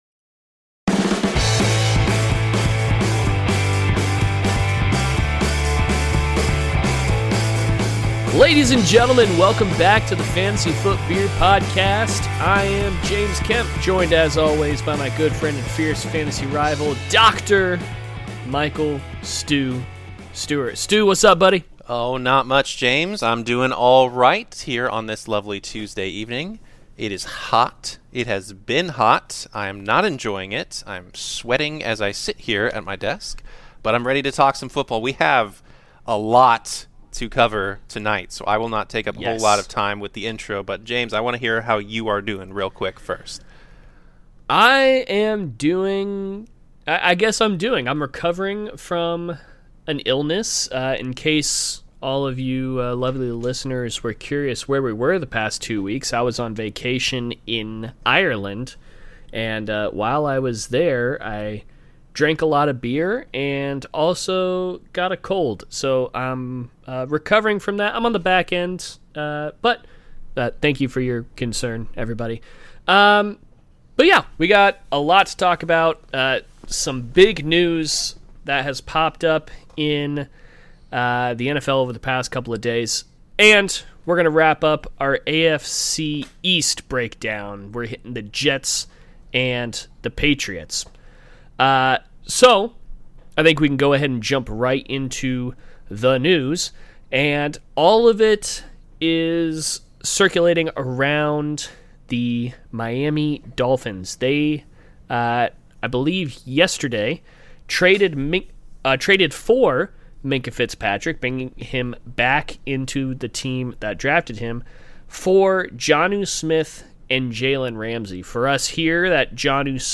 Wherein the gentlemen discuss the bottom half of the AFC East